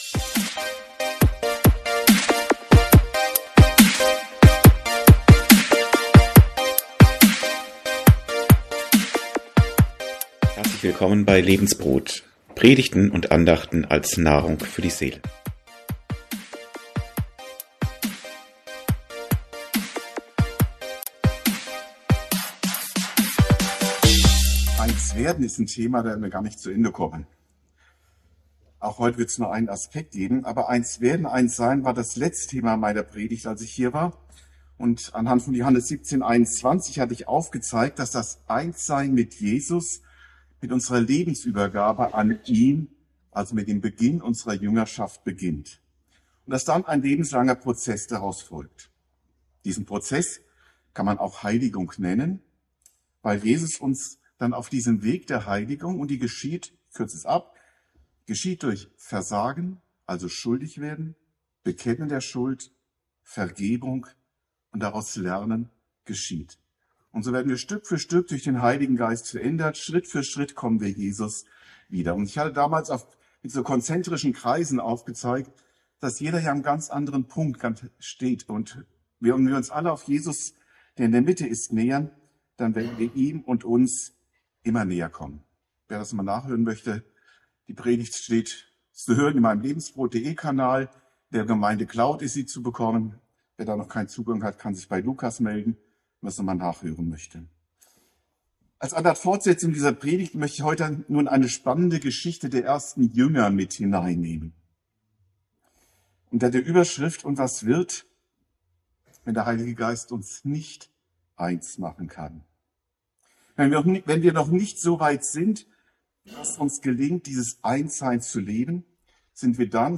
Eins werden 2 - Wenn der Heilige Geist nicht in die Einheit führt? ~ Predigten u. Andachten (Live und Studioaufnahmen ERF) Podcast